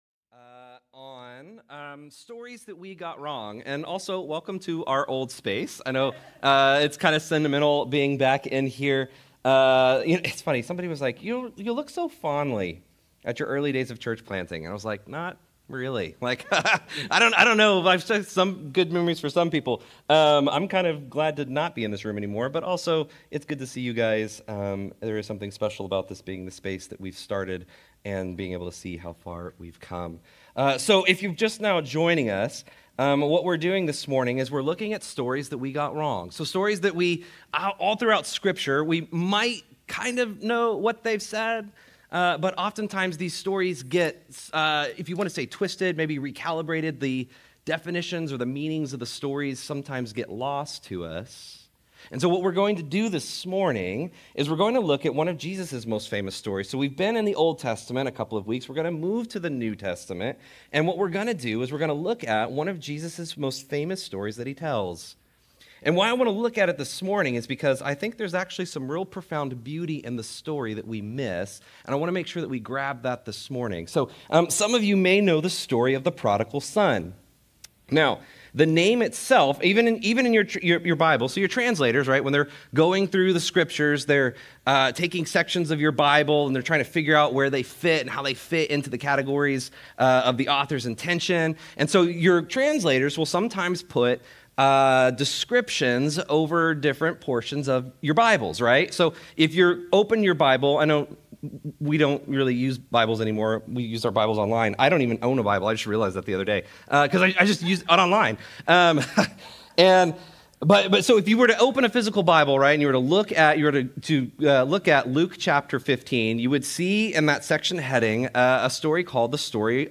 Restore Houston Church Sermons God's Love Has No Final Straw Jun 23 2025 | 00:36:05 1x 00:00 / 00:36:05 Subscribe Share Apple Podcasts Overcast RSS Feed Share Link Embed